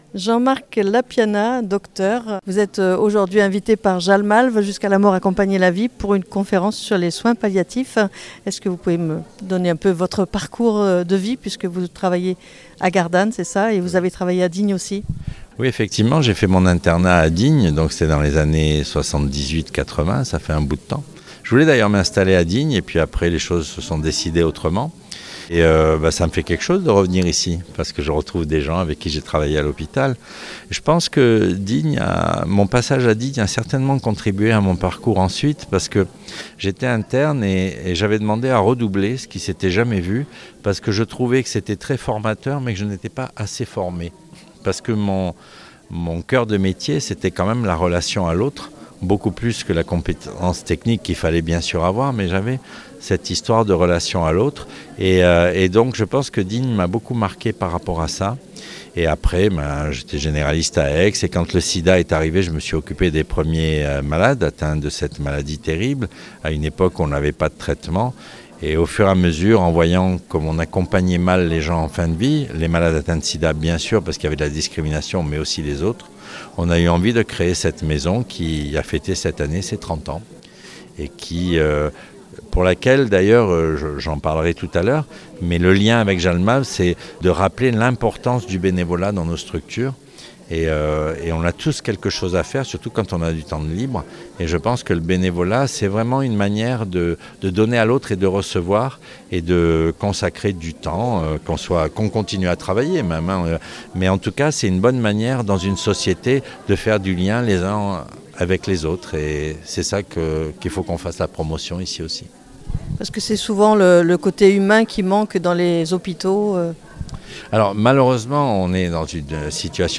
Parmi le public, on pouvait compter une soixantaine de personnes.